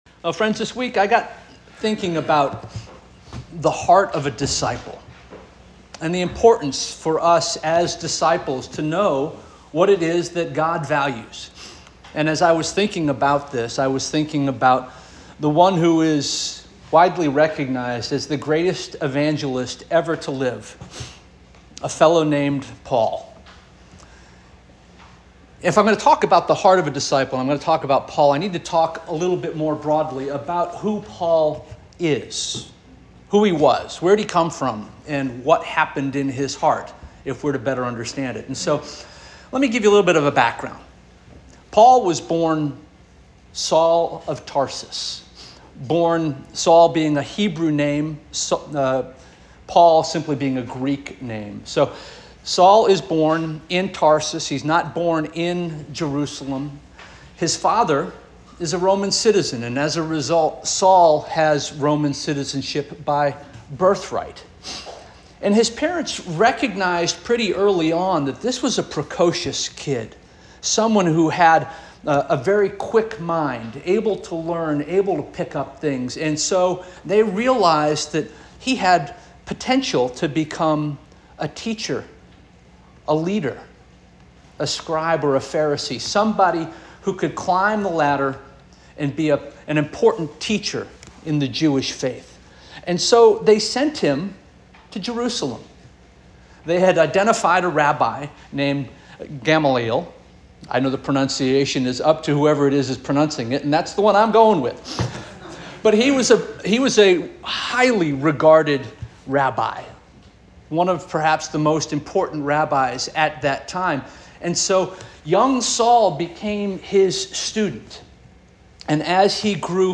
August 11 2024 Sermon - First Union African Baptist Church